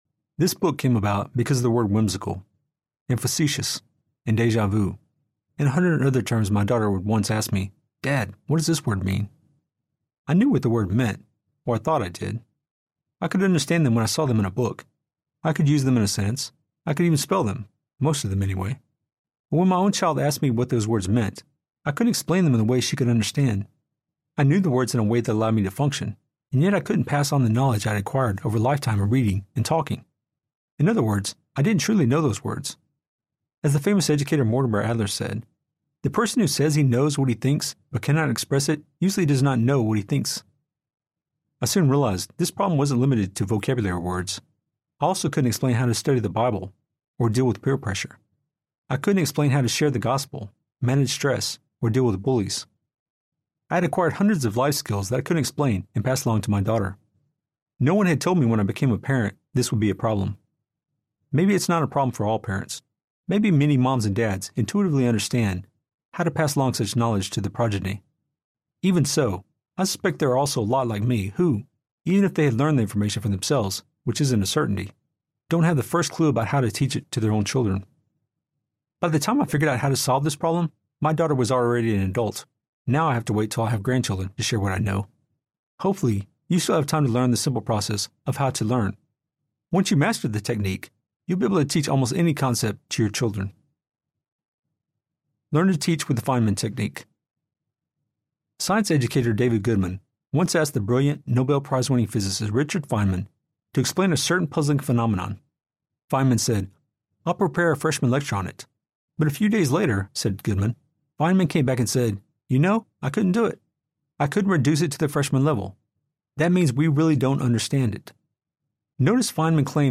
The Life and Faith Field Guide for Parents Audiobook
Narrator